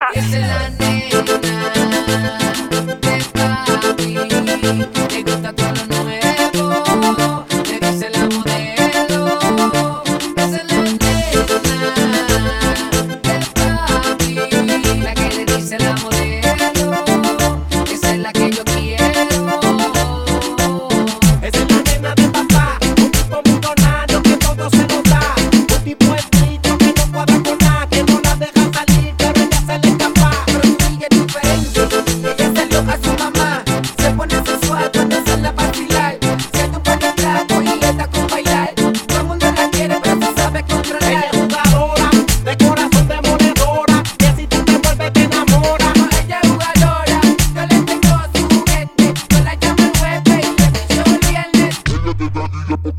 • Качество: 256, Stereo
зажигательные
веселые
заводные
Reggaeton